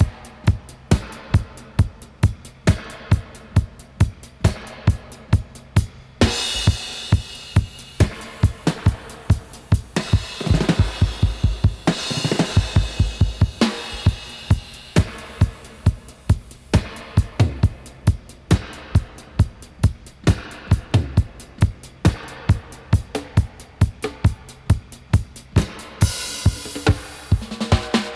136-DUB-01.wav